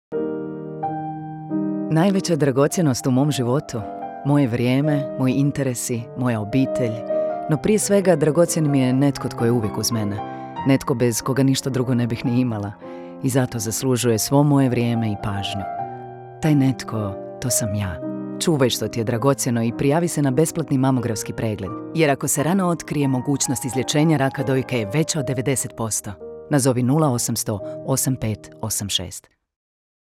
Radijski spot Nacionalnog programa ranog otkrivanja raka dojke